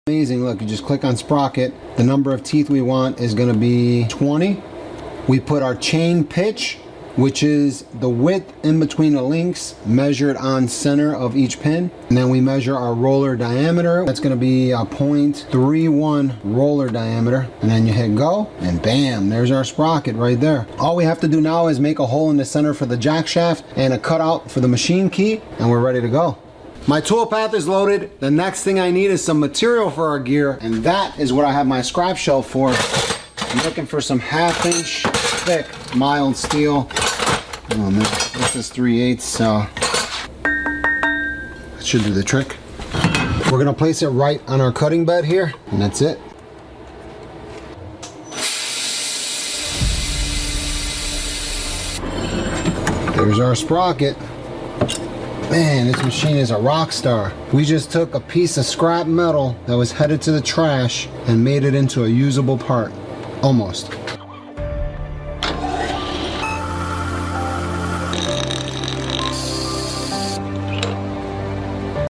Turbo Diesel Go Kart Big Gear sound effects free download
Turbo Diesel Go-Kart Big Gear Top Speed FULL SEND